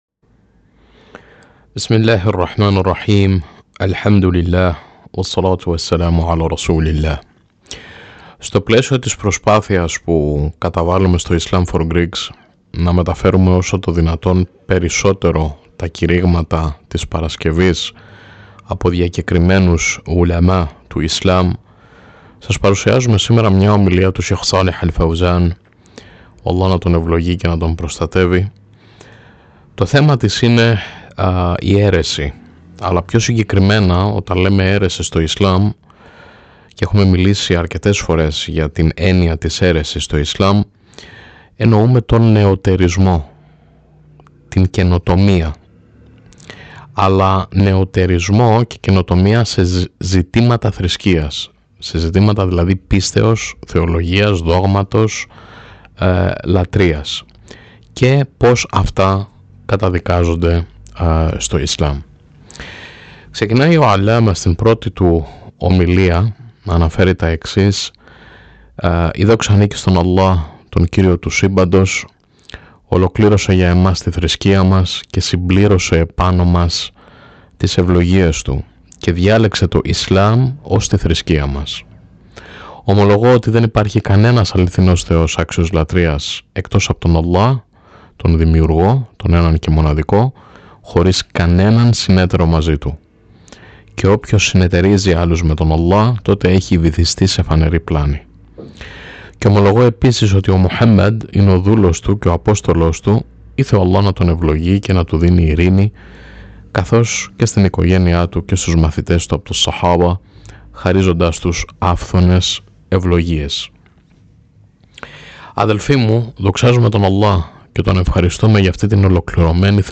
Νέο κήρυγμα της παρασκευής: Ο κίνδυνος του νεωτερισμού και της καινοτομίας στη θρησκεία – التحذير من البدع- خطبة الجمعة
Στο πλαίσιο της προσπάθειας που καταβάλλουμε στο IslamForGreeks, να μεταφέρουμε όσο το δυνατόν περισσότερο τα κηρύγματα της Παρασκευής από διακεκριμένους λόγιους (Ουλεμά) του Ισλάμ, σας παρουσιάζουμε σήμερα μια ομιλία του Σέηχ Σάλιχ αλ Φαουζάν. Το θέμα της είναι η αίρεση, και πιο συγκεκριμένα ο νεωτερισμός ή η καινοτομία σε ζητήματα θρησκείας, είτε αυτά αφορούν τη λατρεία είτε τη θεολογία, και πώς αυτά καταδικάζονται στο Ισλάμ.
bidah-tahdhir-khutba-greek.mp3